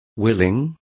Complete with pronunciation of the translation of willing.